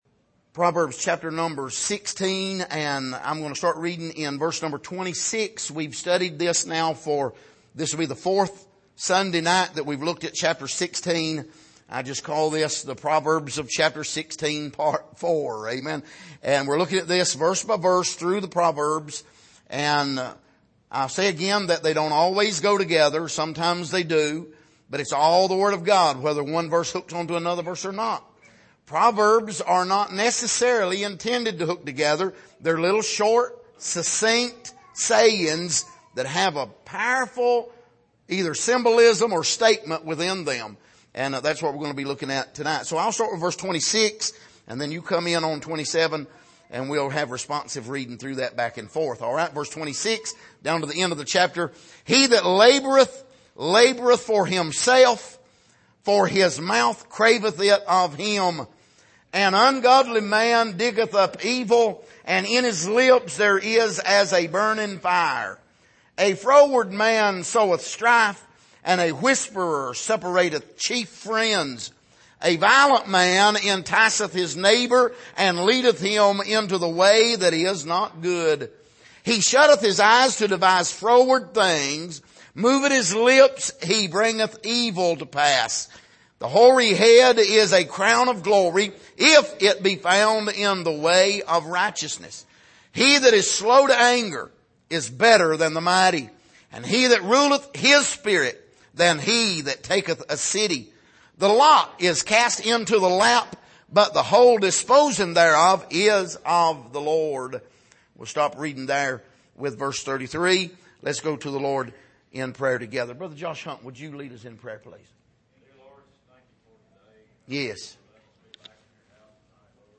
Passage: Proverbs 16:26-33 Service: Sunday Evening